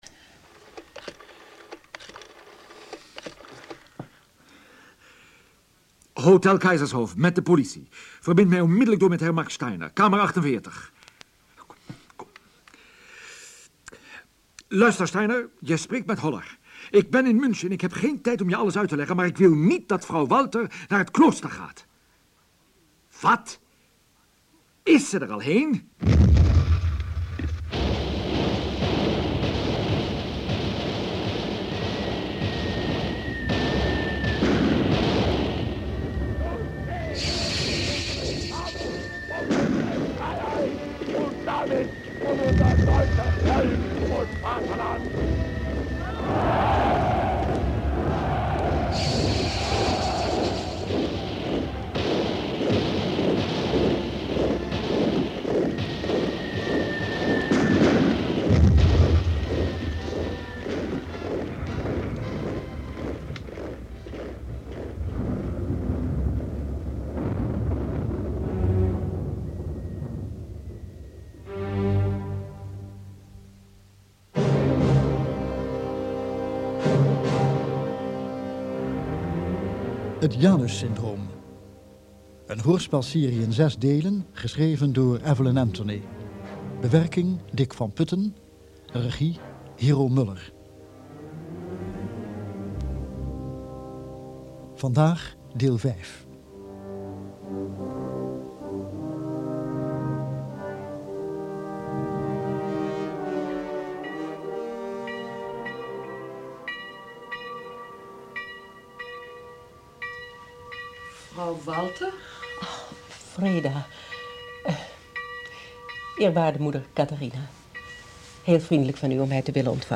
Edmond Classen speelt Max Steiner